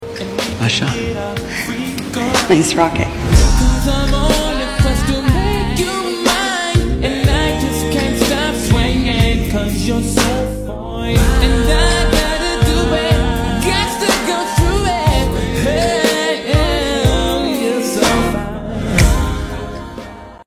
(sorry for the quality media encoder is still broken)